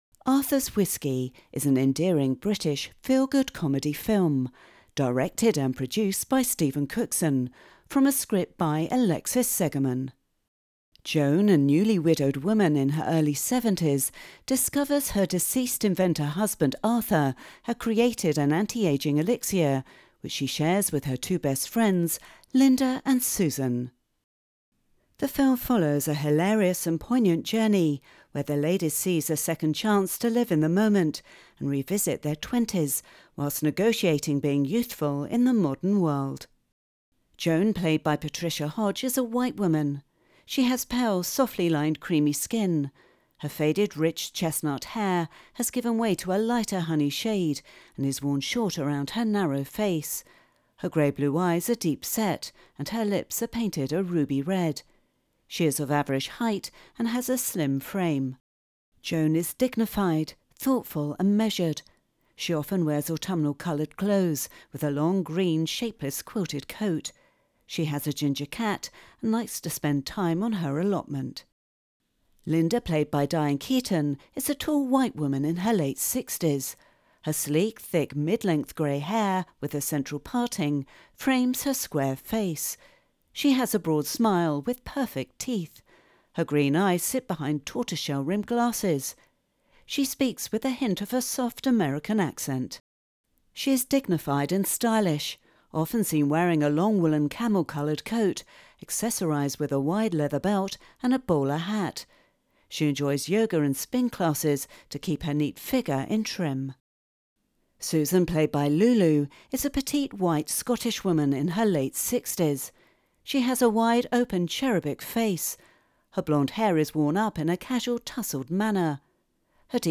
Arthurs_Whisky_AD_Intro.wav